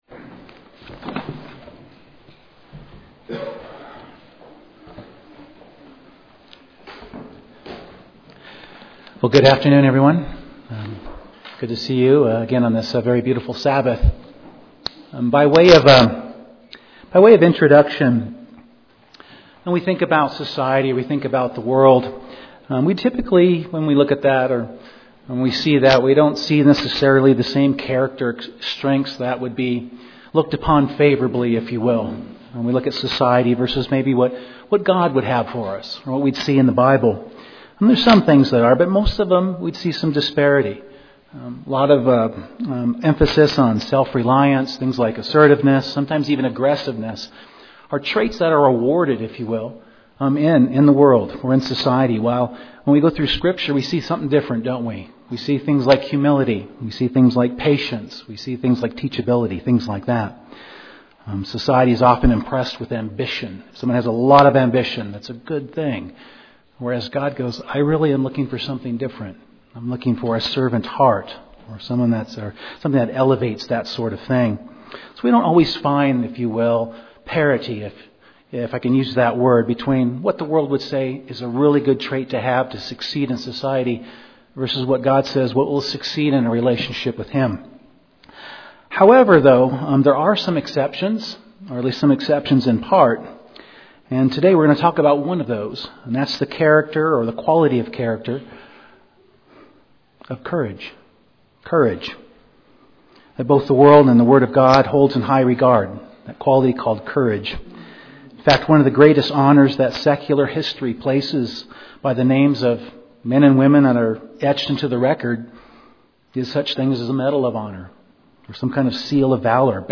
One of the character traits that God asks of His people is that they demonstrate courage (Joshua 1:6-9, 23:6). The sermon focuses on where this quality called courage comes from and how it helps us stand up for God no matter what spiritual challenges or difficulties that may arise .